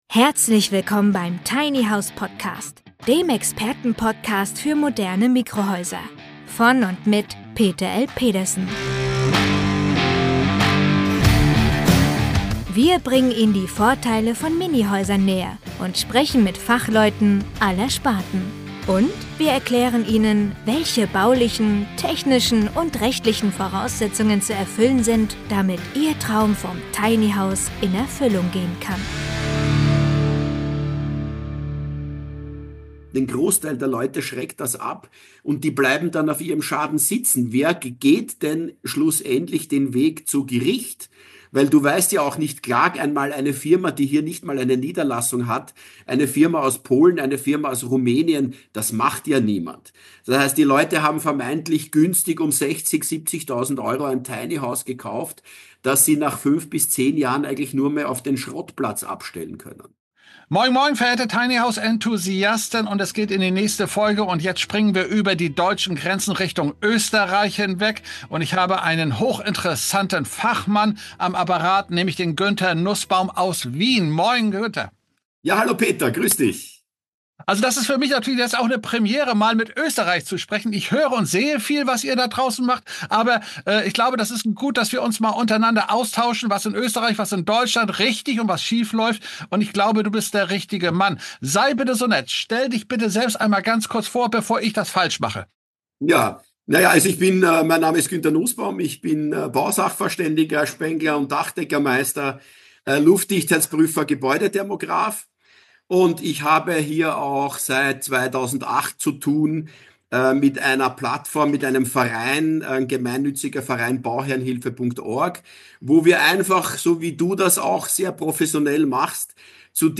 „Pfusch am Bau“-TV Star Günther Nussbaum im Gespräch.